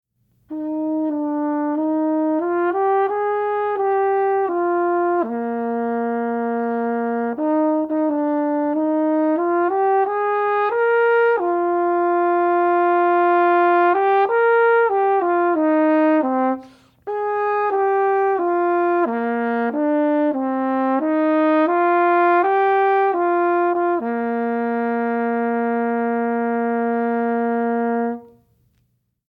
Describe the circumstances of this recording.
(All performed on a stock Yamaha 204M Marching Mellophone)